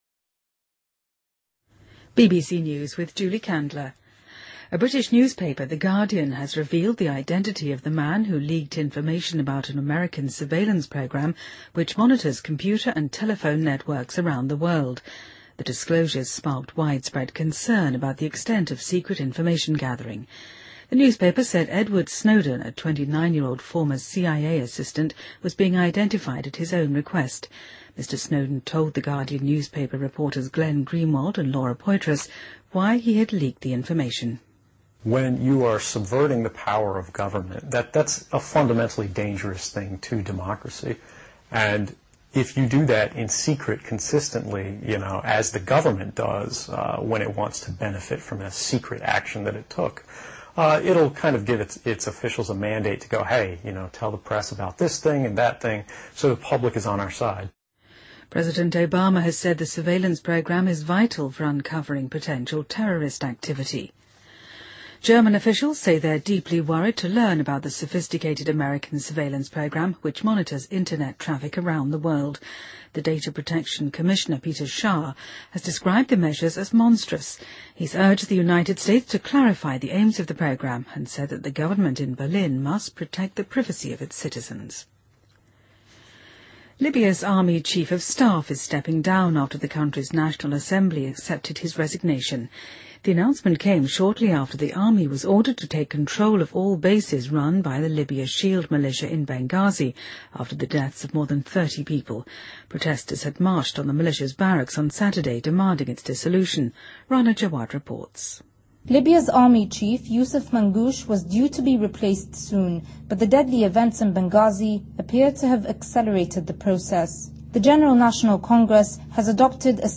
BBC news,2013-06-10